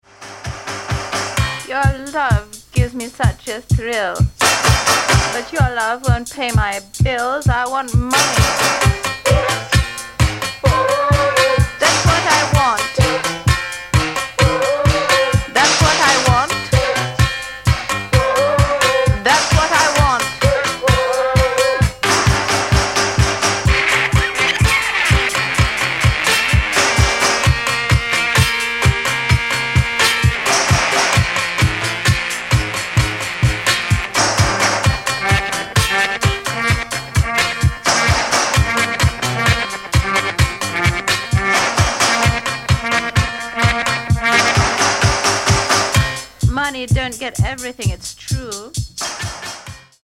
Genere:   Disco | Pop | New Wave